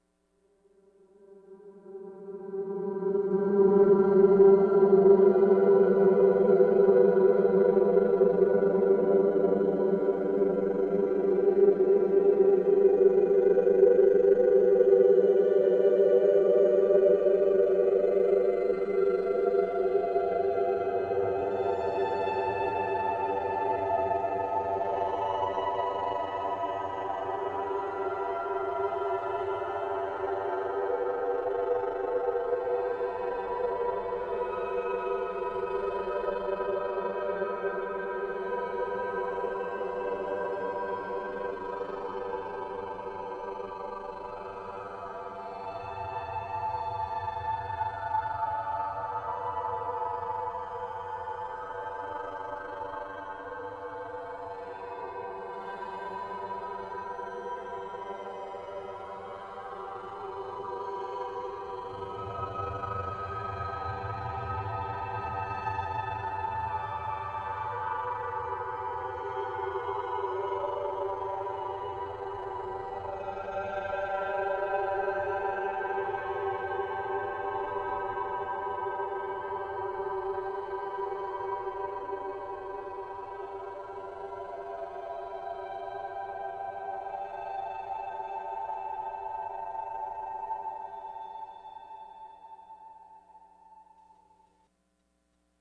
Source: Harmonic gliss on G (7:58-9:33)